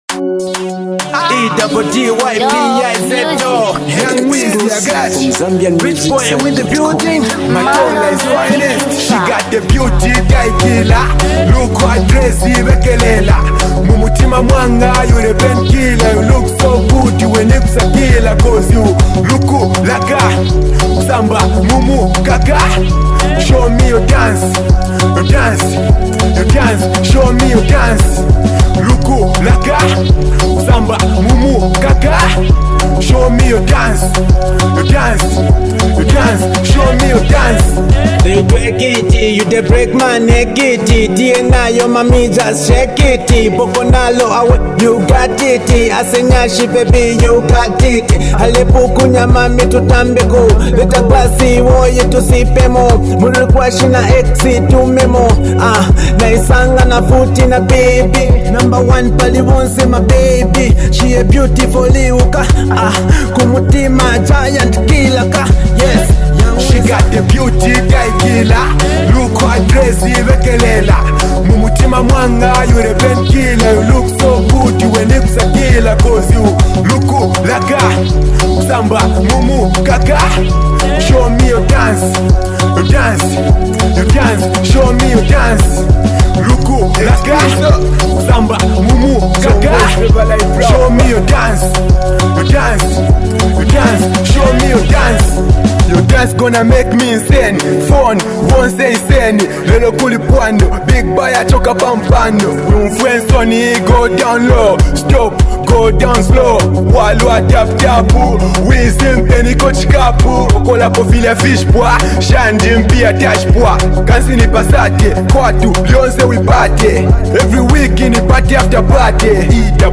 dancehall jam